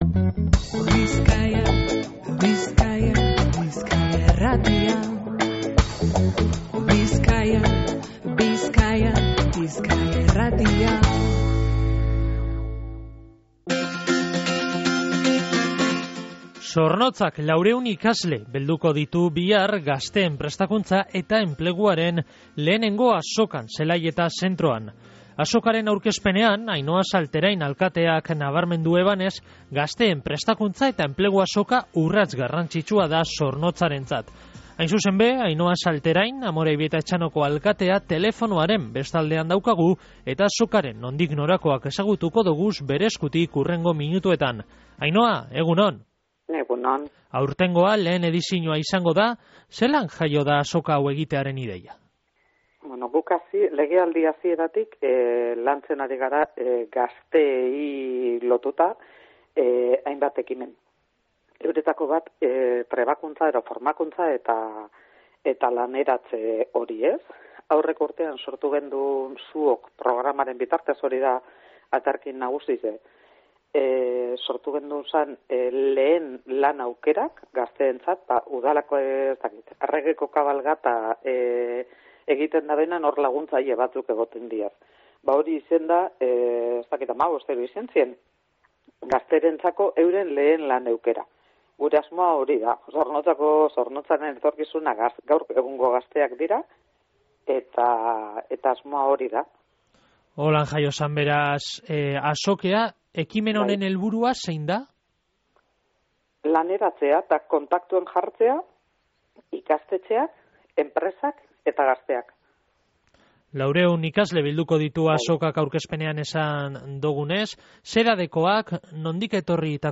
Horren harira, Ainhoa Salterain Amorebieta-Etxanoko alkateagaz egin dogu berba gaurko Goizeko Izarretan irratsaioan. Esan deuskunez, egitasmo honen helburua udalerriko gazteei etorkizun akademiko eta profesionala erabagitzen laguntzeko baliabideak, orientazinoa eta harreman zuzena eskaintzea da.